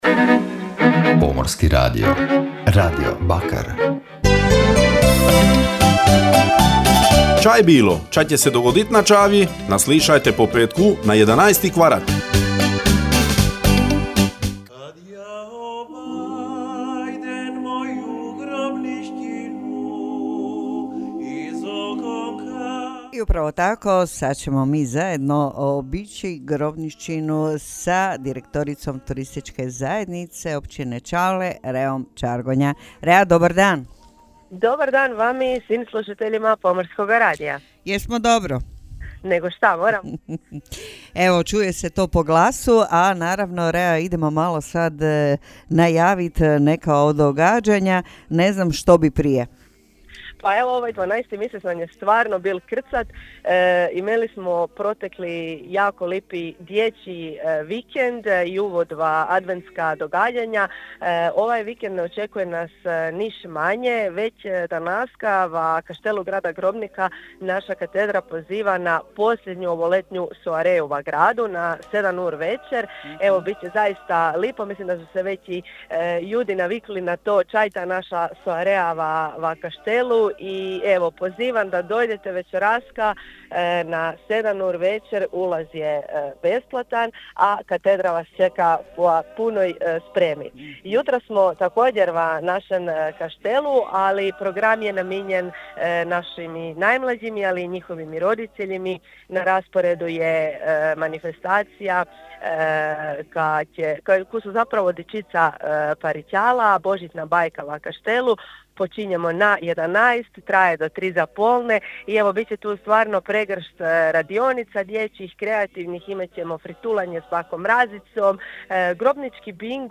[INTERVJU]; BOŽIĆNA BAJKA VA KAŠTELU!